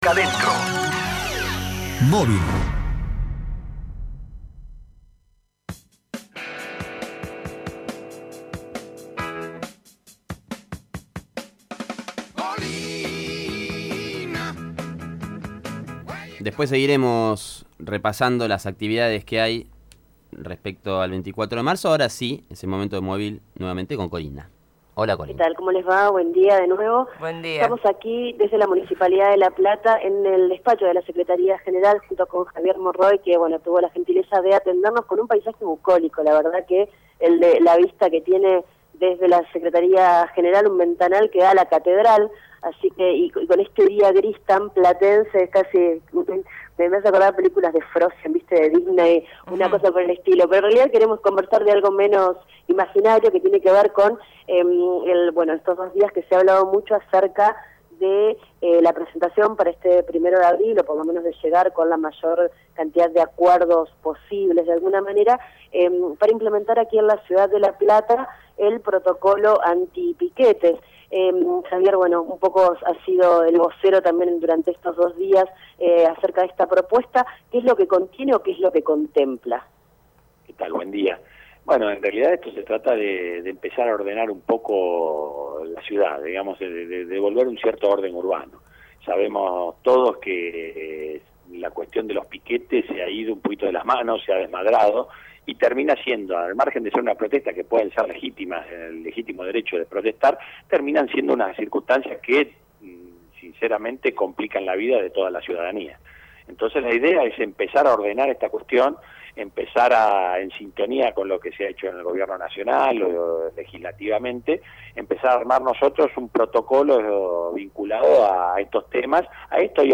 piquete(18/3/2016) Luego de que varios conductores atravesaran Plaza Moreno días atrás, producto de una jornada de protestas, el Intendente Julio Garro enviará un proyecto al Concejo Deliberante para garantizar la circulación y controlar los cortes de calle. En diálogo con el móvil de Radio Estación Sur, el Secretario General de la Municipalidad, Javier Mor Roig, se refirió a esta polémica medida, impulsada por el Ministerio de Seguridad de La Nación.